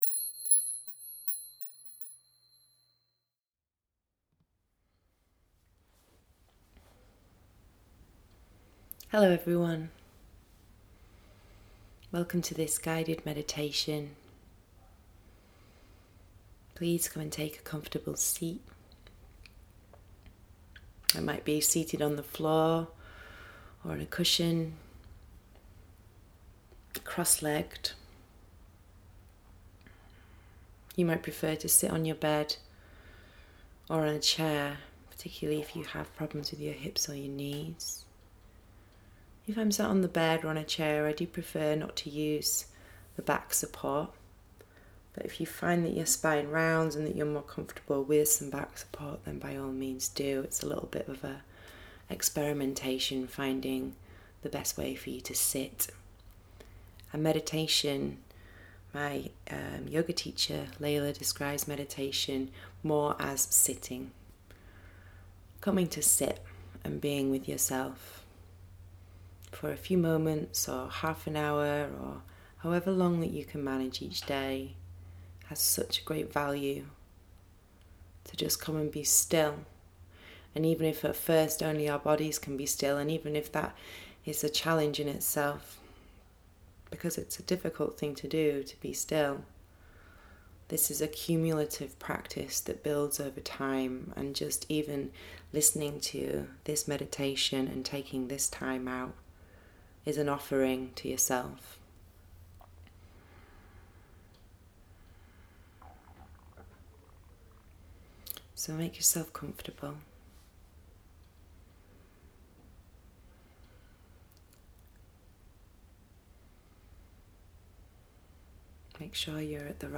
Guided Meditations
yoga-nidra-1-1.mp3